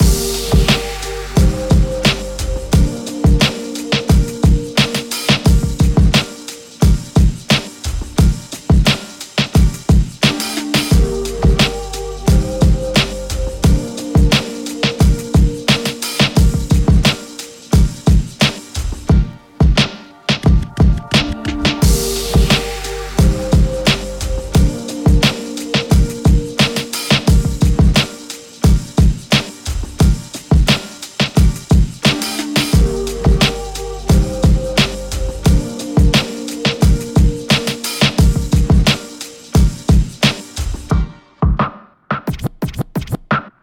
ヒップホップのリズムトラックのループ素材です。
各ループ素材はBPM（テンポ）を88に統一した16小節のビート・トラックとなっています。